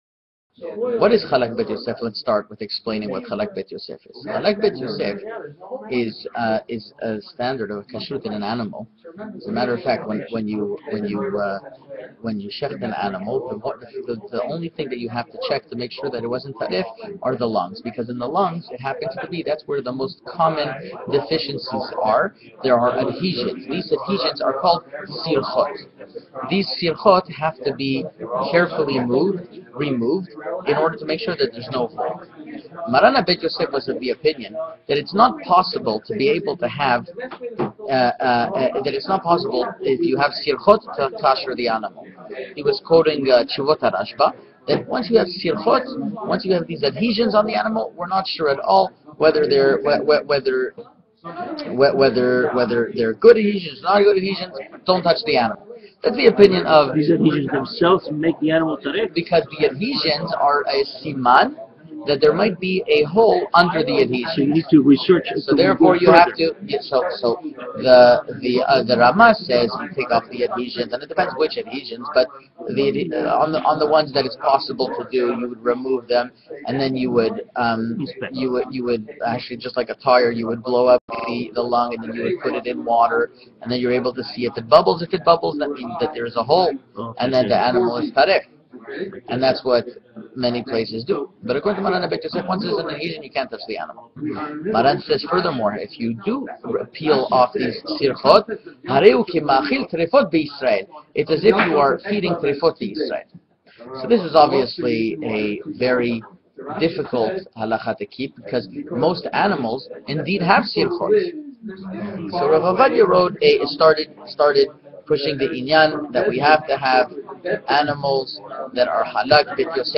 An audio Shiur